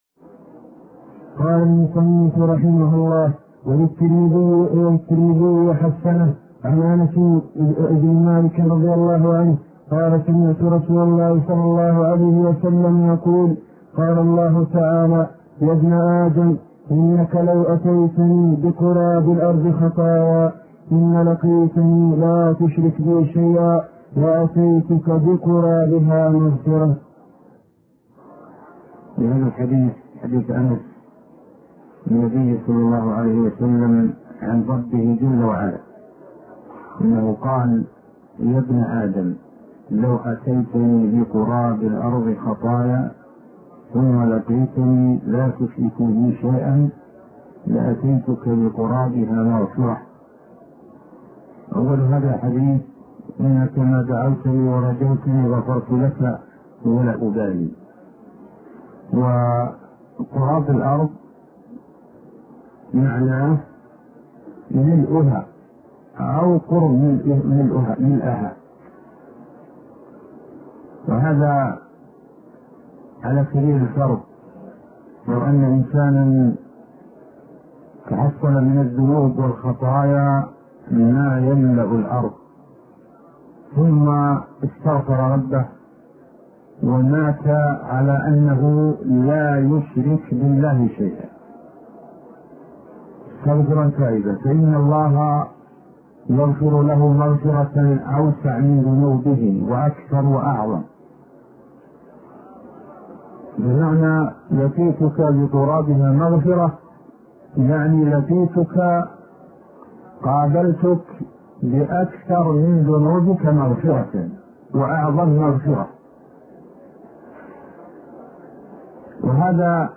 عنوان المادة الدرس ( 14) شرح فتح المجيد شرح كتاب التوحيد تاريخ التحميل الجمعة 16 ديسمبر 2022 مـ حجم المادة 23.11 ميجا بايت عدد الزيارات 212 زيارة عدد مرات الحفظ 114 مرة إستماع المادة حفظ المادة اضف تعليقك أرسل لصديق